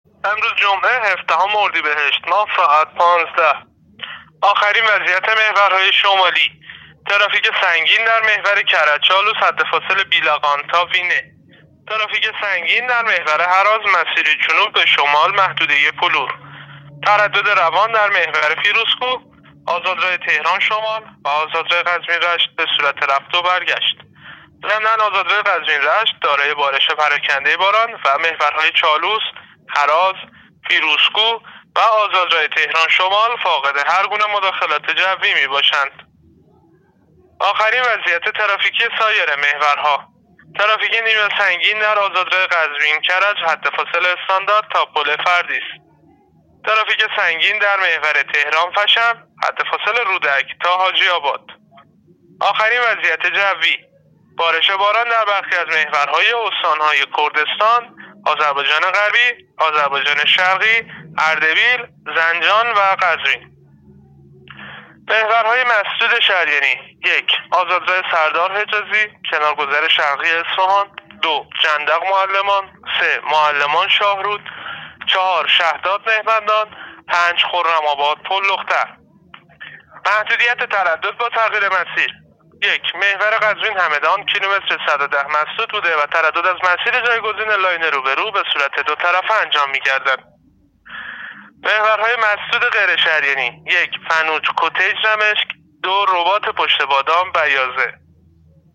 گزارش رادیو اینترنتی از آخرین وضعیت ترافیکی جاده‌ها تا ساعت ۱۵ هفدهم اردیبهشت